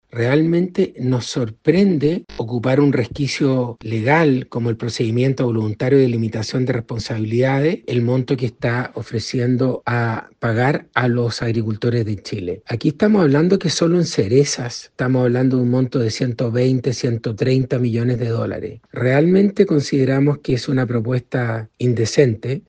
Al respecto, el presidente de la Sociedad Nacional de Agricultura (SNA), Antonio Walker, dijo que considerando la relación con la empresa, la respuesta es sorpresiva y la propuesta de indemnización indecente.